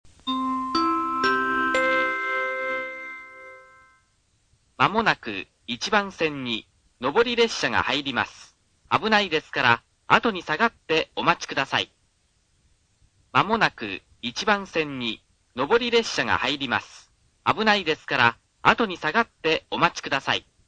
この駅は放送・メロディーとも音量が非常に大きいです。
１番線接近放送